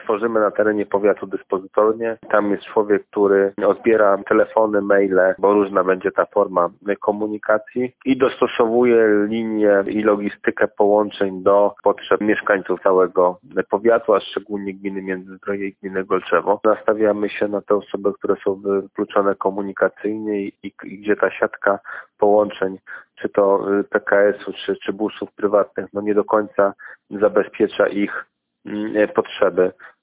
Jak to będzie wyglądało w praktyce, mówi burmistrz Międzyzdrojów Mateusz Bobek.